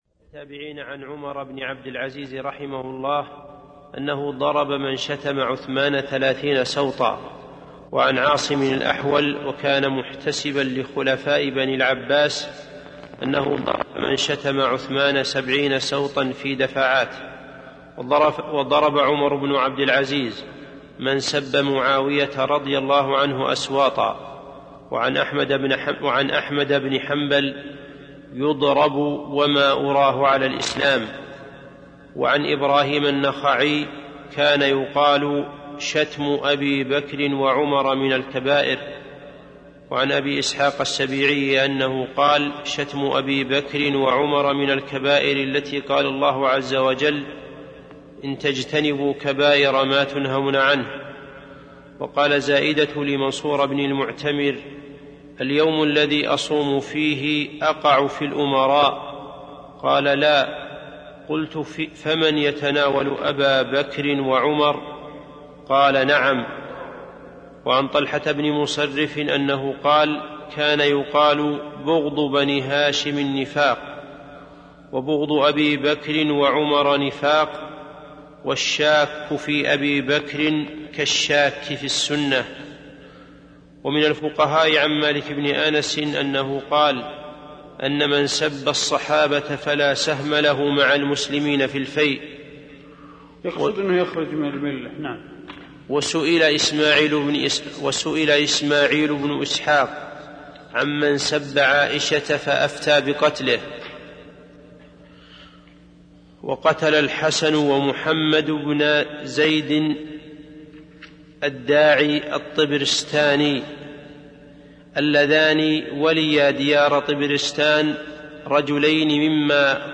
عنوان المادة الدرس (37) شرح أصول إعتقاد أهل السنة والجماعة تاريخ التحميل الأحد 1 يناير 2023 مـ حجم المادة 42.59 ميجا بايت عدد الزيارات 243 زيارة عدد مرات الحفظ 110 مرة إستماع المادة حفظ المادة اضف تعليقك أرسل لصديق